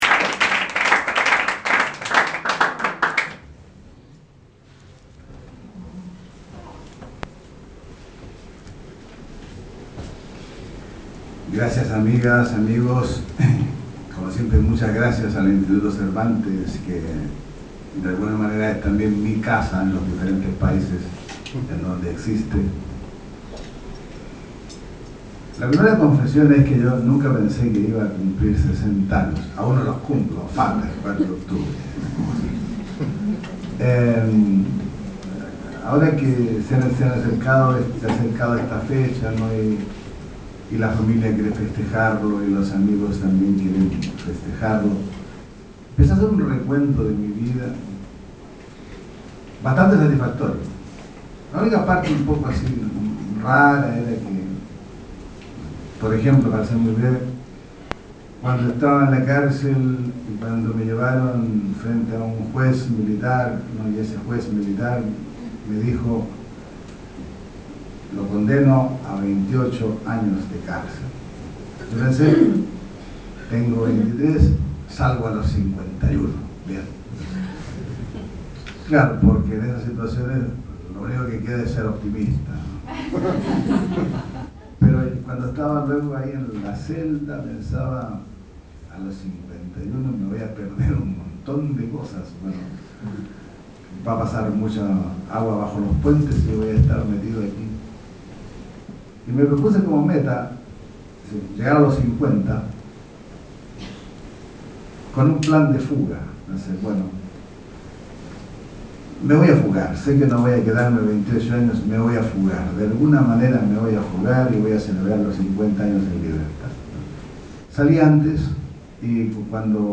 Con motivo de su 60º cumpleaños, el Instituto Cervantes de Milán rinde homenaje al universal escritor chileno Luis Sepúlveda. Con la presencia de Bruno Arpaia, Pino Cacucci y Daniel Mordzinski, que reúne para la ocasión sesenta retratos del escritor.